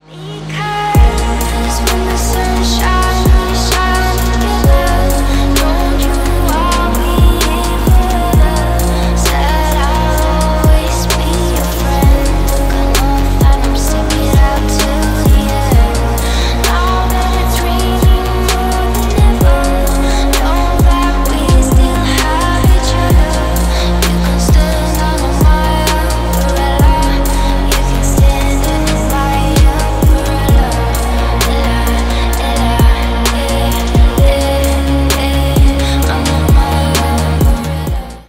Ремикс # Электроника
кавер